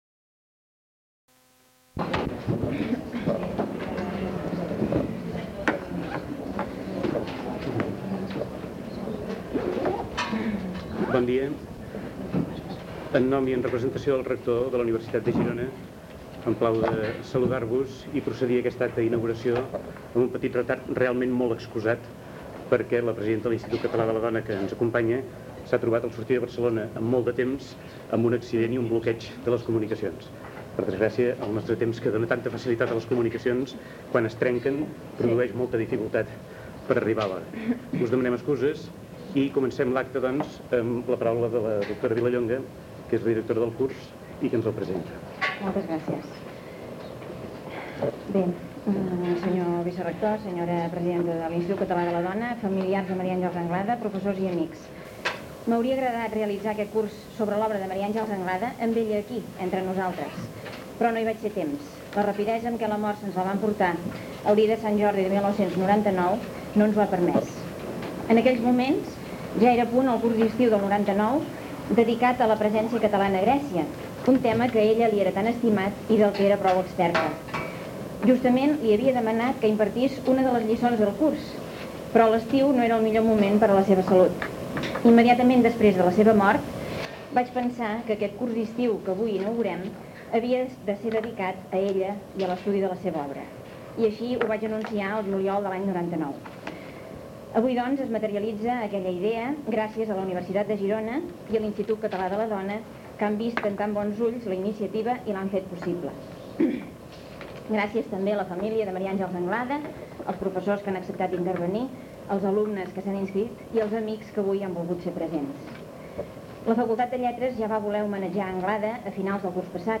Inauguració del Curs d’Estiu de l’any 2000 de l'àrea de Filologia Llatina de la Universitat de Girona dedicat a la figura de l’escriptora Maria Àngels Anglada, que va morir l’any anterior.